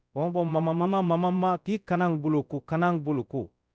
Synthetic_audio_bambara